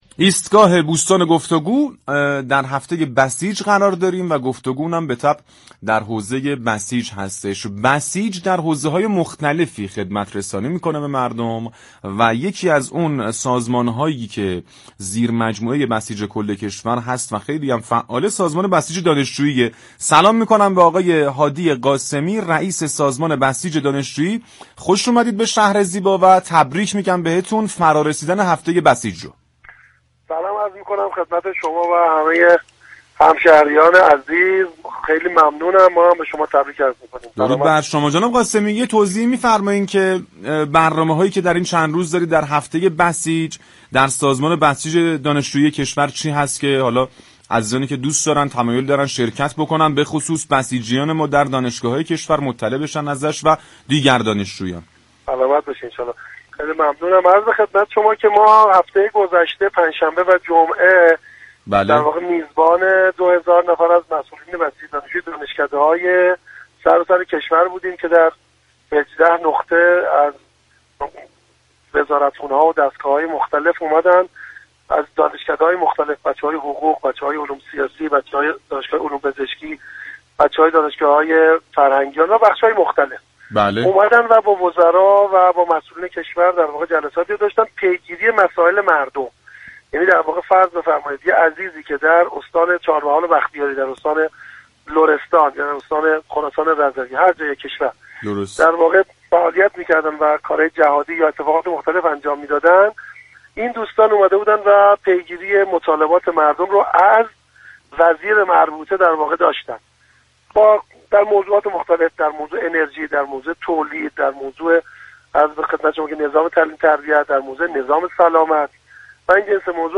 به گزارش پایگاه اطلاع رسانی رادیو تهران، هادی قاسمی رئیس سازمان بسیج دانشجویی كشور در گفت و گو با «شهر زیبا» اظهار داشت: گردهمایی سراسری مسئولان بسیج دانشجویی و دانشجویان بسیجی به مناسبت هفته بسیج با حضور مسعود پزشكیان رئیس جمهور كشورمان جمعه 2 آذر 1403 در حرم مطهر حضرت امام خمینی (ره) برگزار شد.